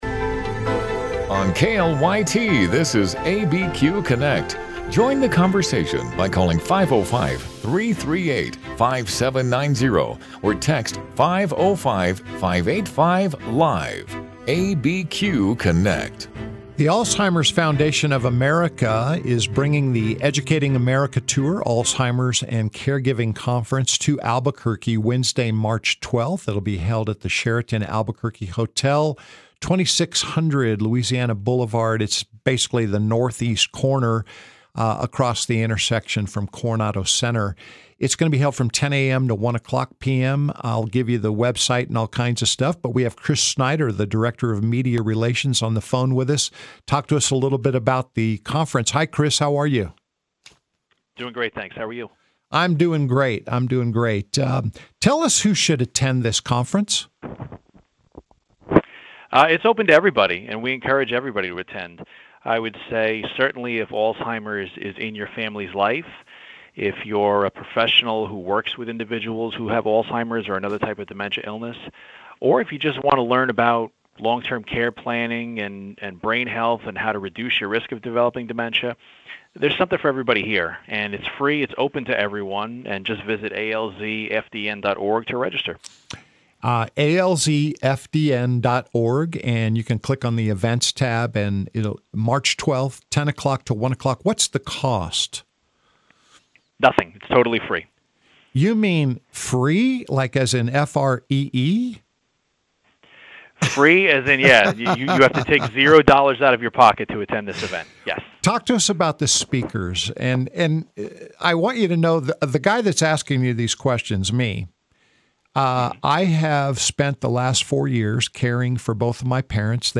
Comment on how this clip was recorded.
Live, local and focused on issues that affect those in the New Mexico area. Tune in for conversations with news makers, authors, and experts on a variety of topics.